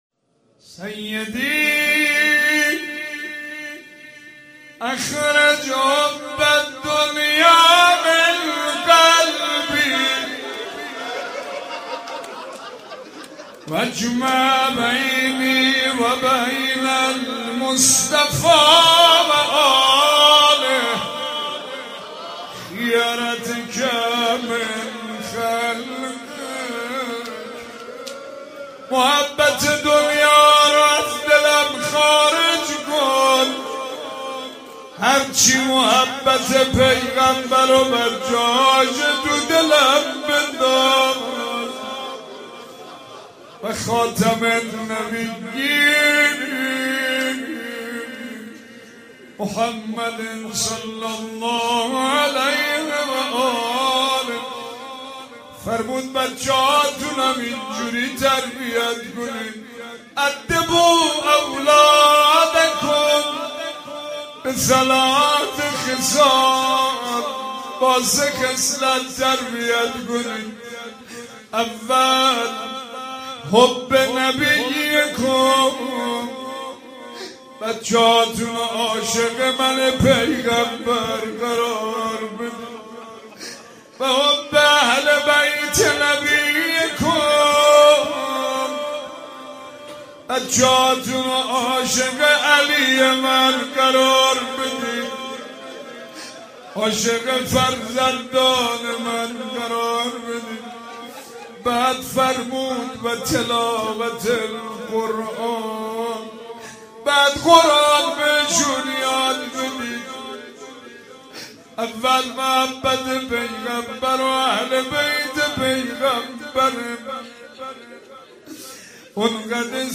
قسمتى از مناجات بسيار زيباى ابوحمزه ثمالی